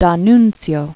(dän-nntsyō), Gabriele 1863-1938.